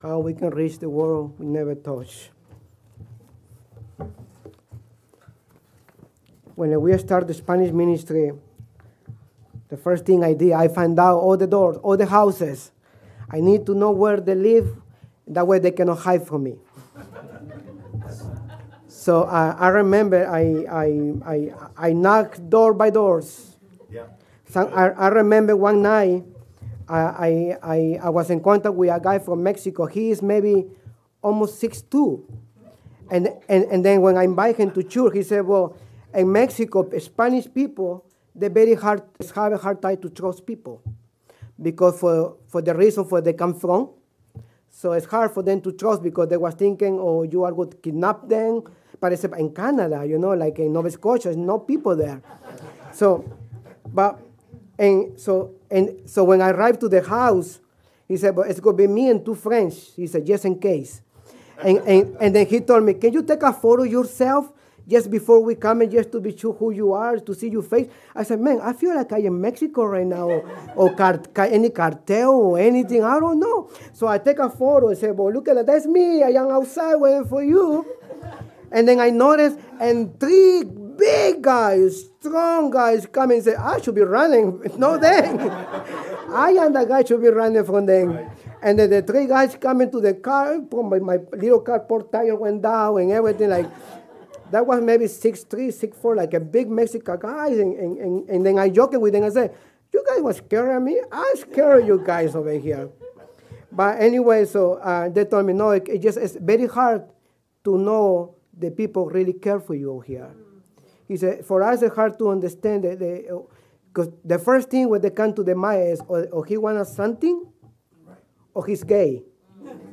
Sermons | Anchor Baptist Church
Mission Conference 2025 - Monday Night